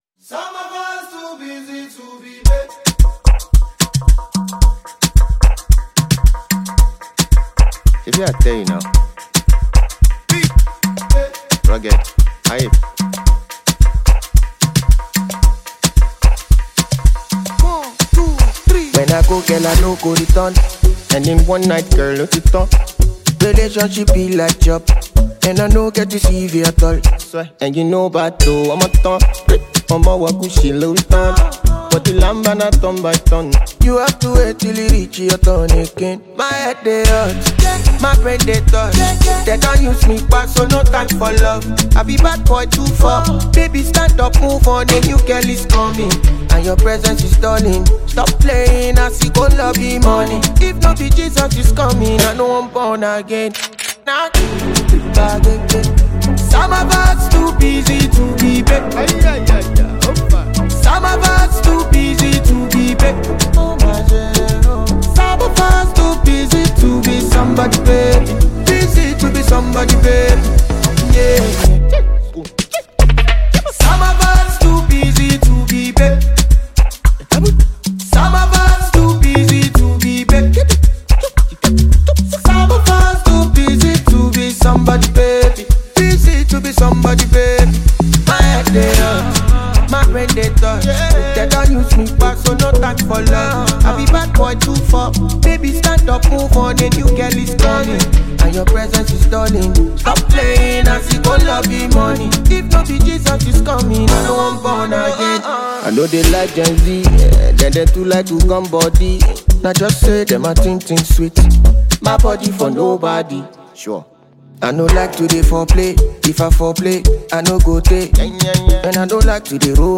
With its enchanting melodies and infectious beats